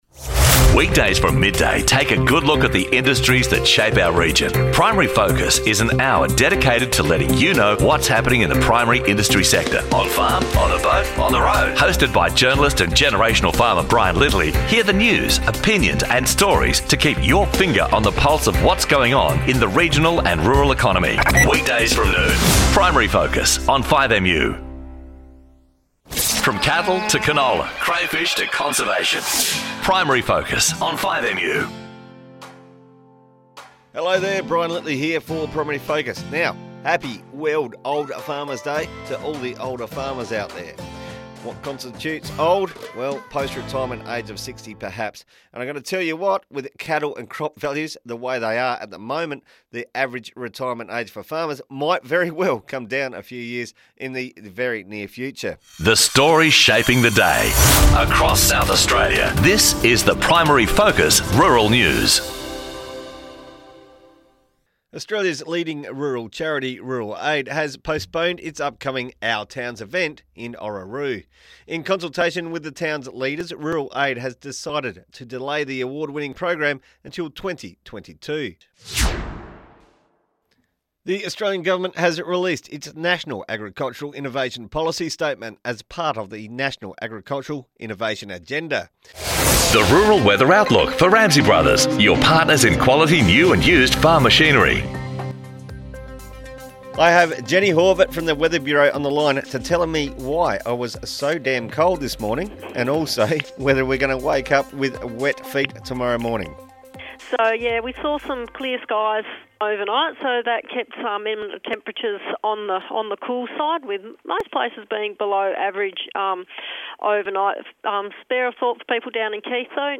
Headliner Embed Embed code See more options Share Facebook X Subscribe Primary Focus takes a close look at the industries that drive the South Australian rural and regional economy. Featuring the latest rural news, interviews with key industry figures and insights into our industries, Primary Focus also has a lot of fun and plays some of the finest music.